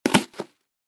Звуки картона
Звук захлопывающейся картонной коробки